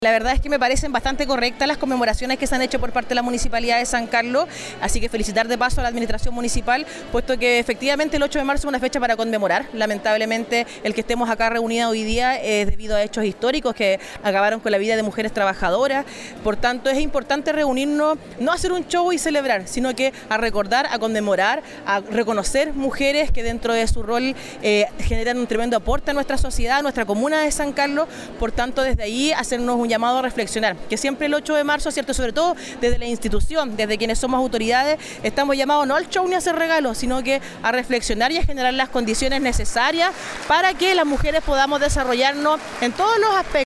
La actividad, realizada en el Centro Cultural, reunió a más de 300 asistentes y contó con la presencia de autoridades locales y regionales, quienes destacaron la importancia de la equidad de género y el rol esencial de la mujer en la comunidad.